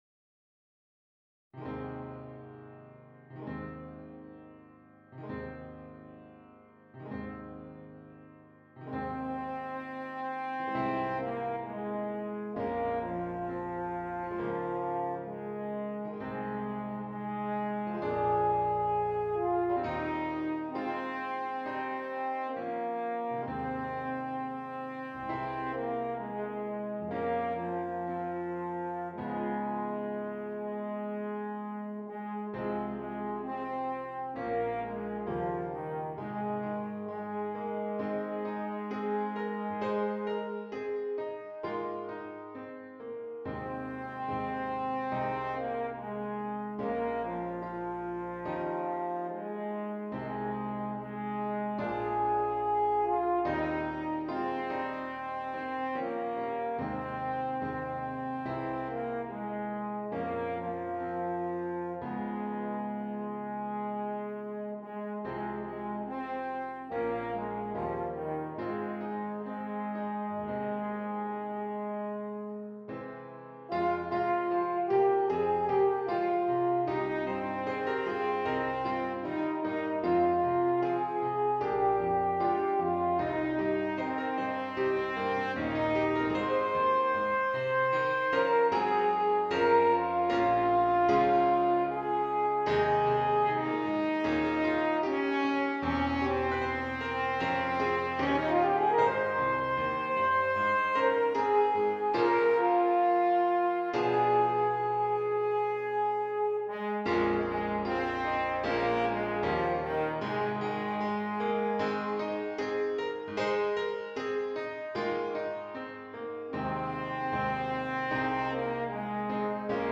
F Horn and Keyboard
Traditional